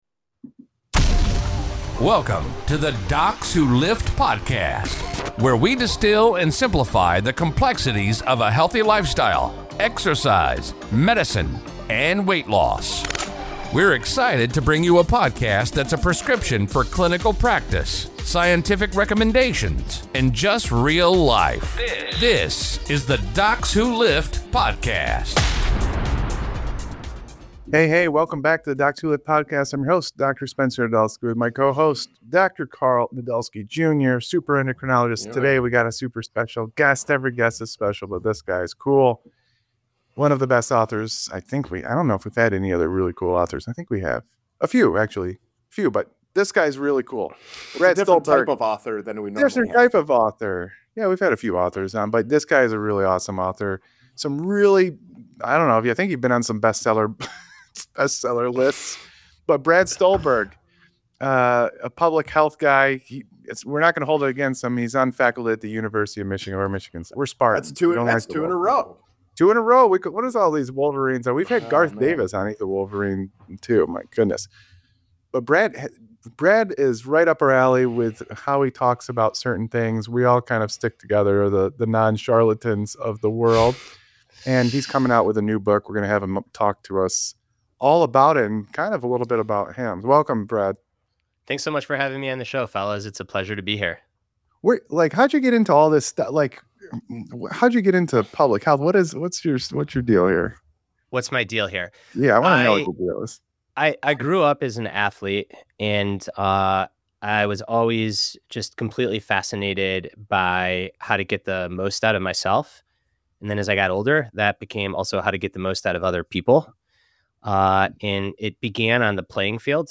Reclaiming Excellence: A Conversation with Brad Stulberg